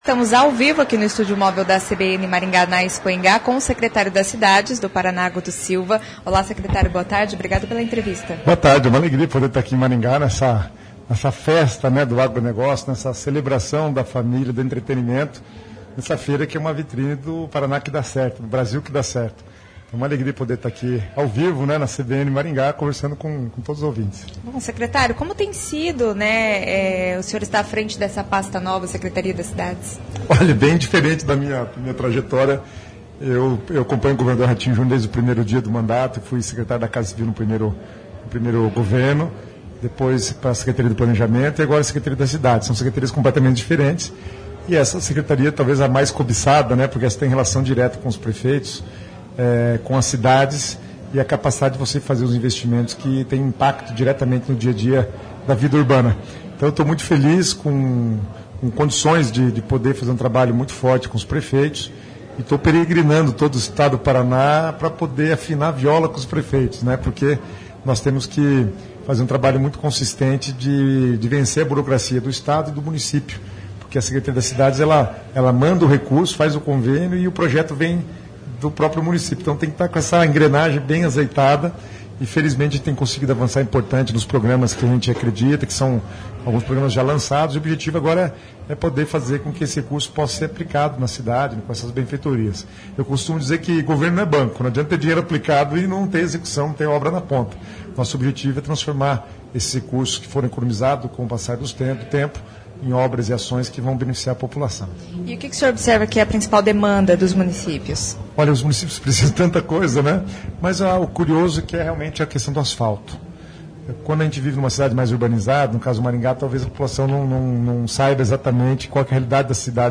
Secretário das Cidades do Paraná, Guto Silva.
Expoingá 2025